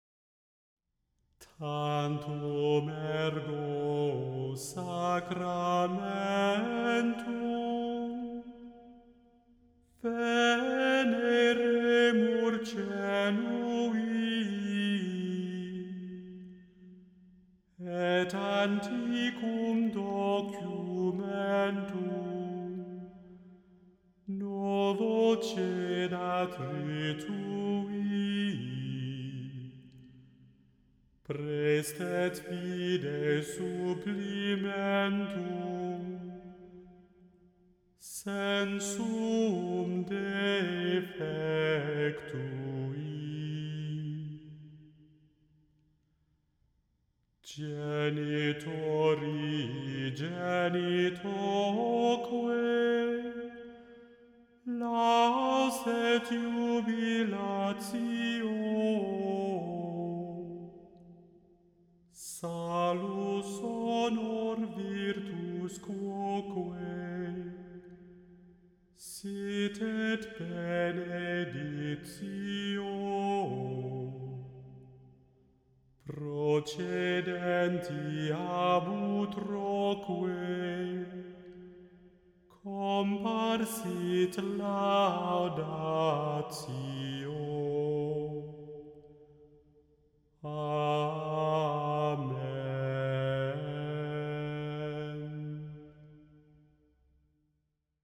The Chant Project – Chant for Today (June 11) – Tantum ergo